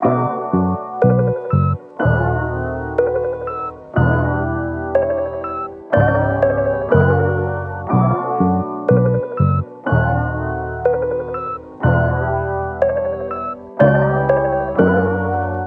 MELODY LOOPS
Chrysler (122 BPM – Bm)
UNISON_MELODYLOOP_Chrysler-122-BPM-Bm.mp3